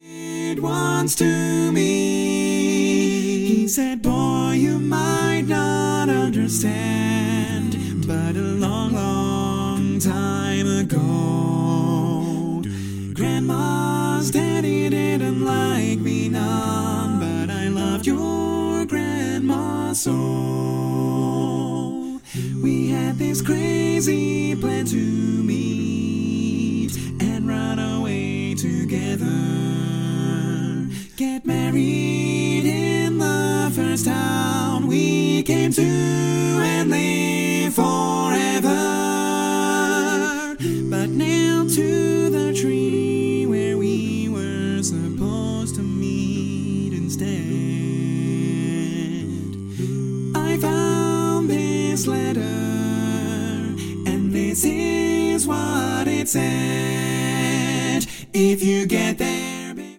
Full mix
Male